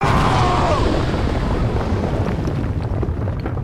treffer.mp3